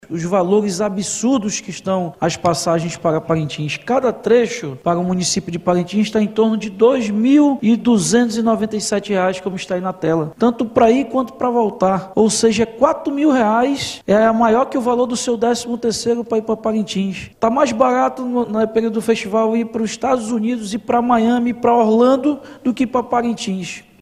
Deputados e deputadas estaduais da Assembleia Legislativa do Amazonas (Aleam) debateram em Sessão Plenária o alto preço das passagens aéreas para Parintins, no mês de junho, que despertou especial atenção dos parlamentares.
O presidente da Aleam, Roberto Cidade (União Brasil), denunciou o aumento abusivo do valor das passagens aéreas para Parintins durante o mês de junho, por causa do Festival Folclórico de Boi Bumbá.